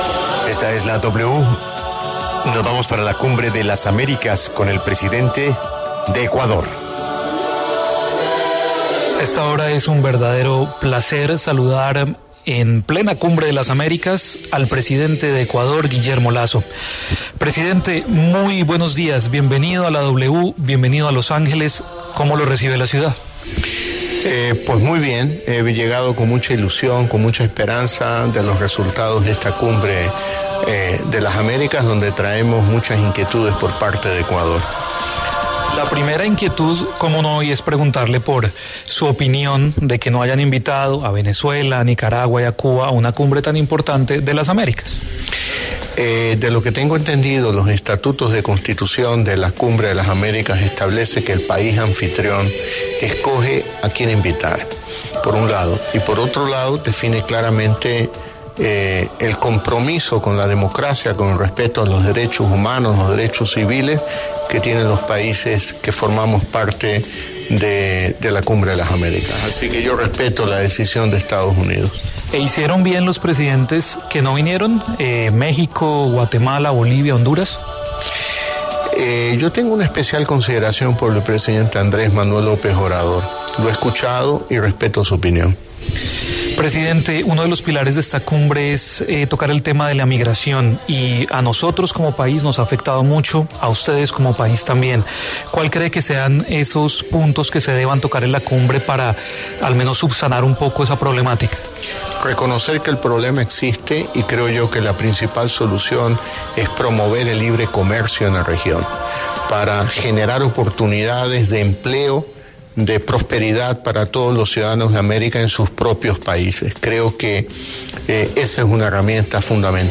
Desde la Cumbre de las Américas, La W conversó con el presidente de Ecuador, Guillermo Lasso, sobre los desafíos en la región.
En el encabezado escuche la entrevista completa con Guillermo Lasso, el presidente de Ecuador.